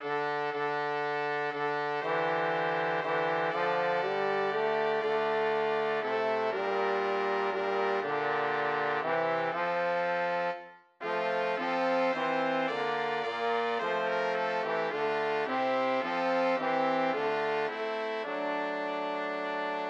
EG 8 an der Orgel eingespielt
Die Melodie weist eine in älteren Kirchenliedern nur äußerst selten anzutreffende rhythmische Besonderheit auf: Die beiden ersten Zeilen stehen im 6/4-Takt, die beiden letzten im 4/4-Takt. Dieser metrischen Zweiteilung entspricht eine tonale.
Im später aufkommenden und heute noch gebräuchlichen Dur-Moll-System würde man von d-Moll und F-Dur sprechen.